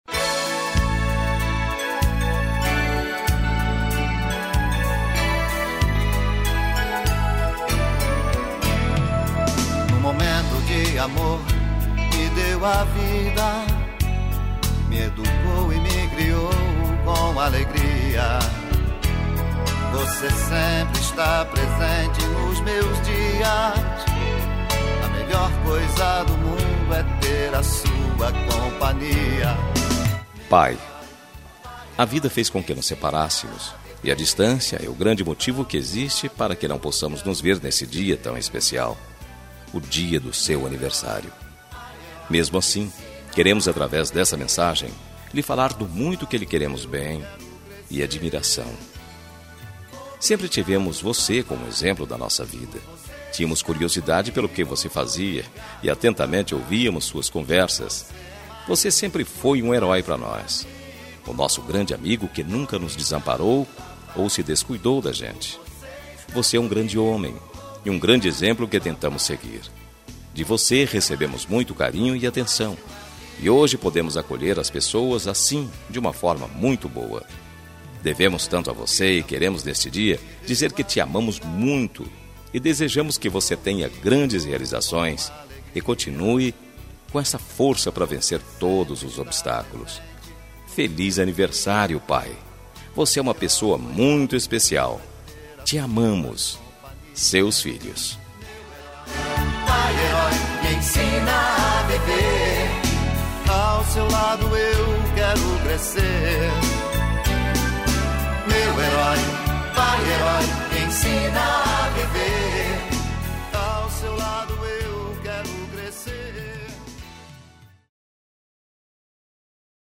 Telemensagem de Aniversário de Pai – Voz Masculina – Cód: 1514 Distante